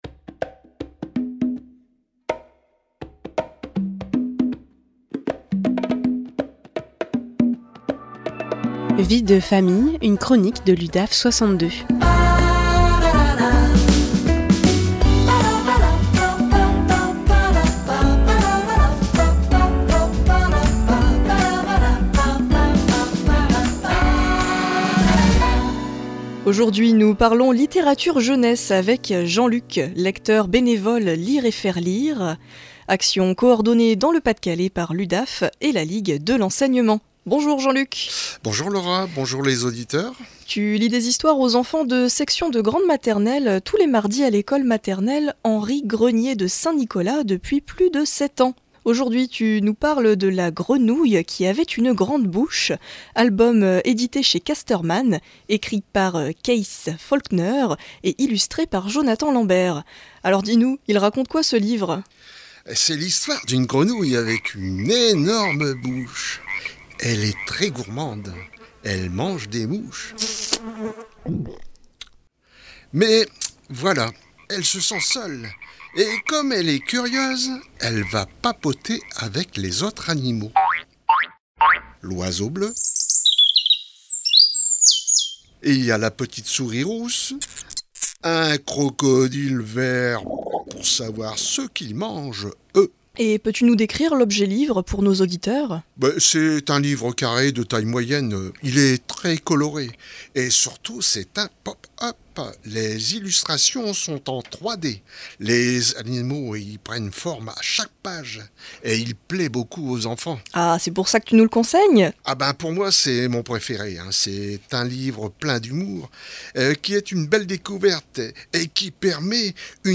Vie de Famille, une chronique de l’Udaf62 en live sur RADIO PFM 99.9